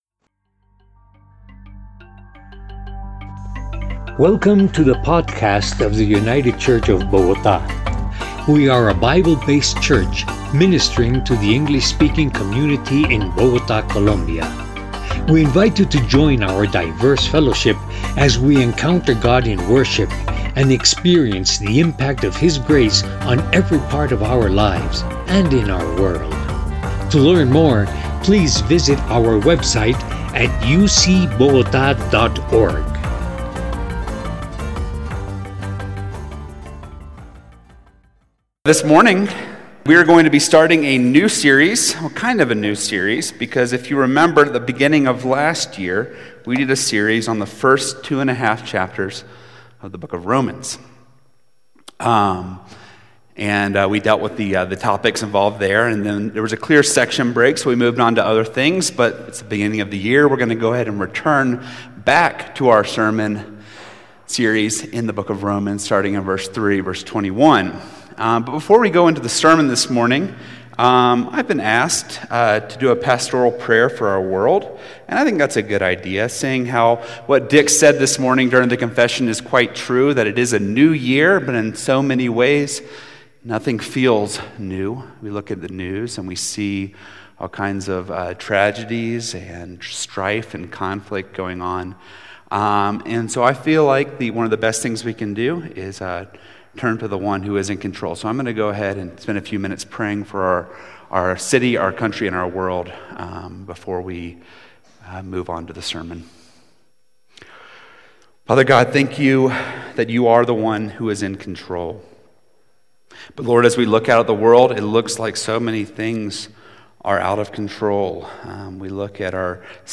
Unranked – United Church of Bogotá